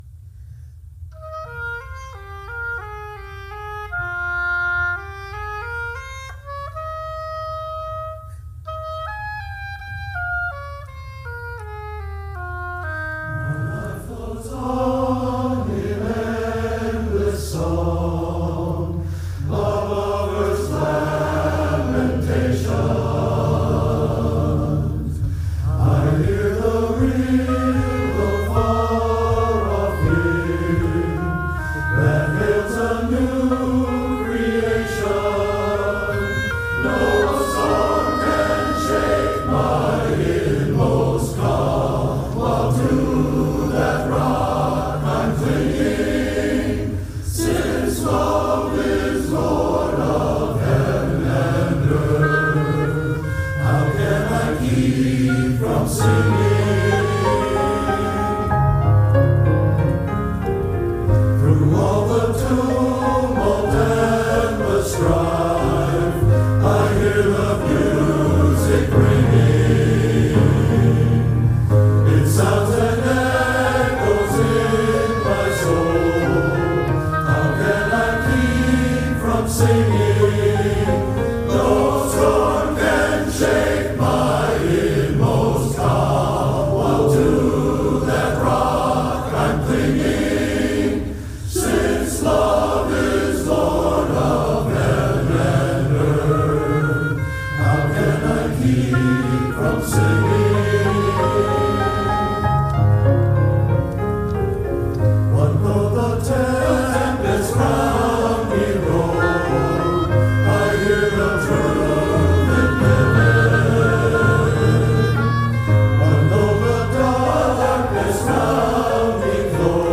First Congregational Church Of Southington, Connecticut - April 22, 2023